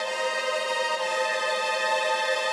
GS_Viols_95-C2.wav